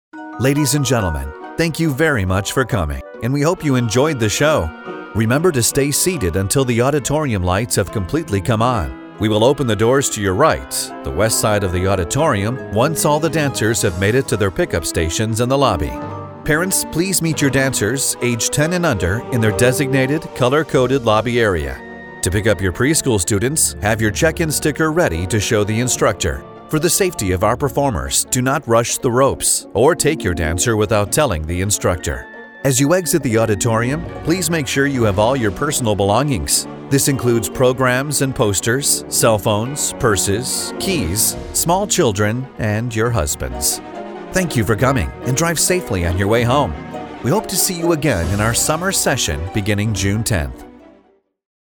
语言：普通话 （A07男外籍）
特点：激情大气 活力时尚
A07外籍英语--活动开场PA announcer Demo_Announcement Fairytale.mp3